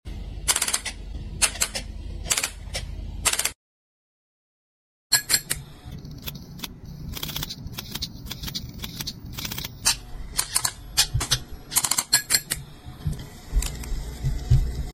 This old timey style telegraph actually sound effects free download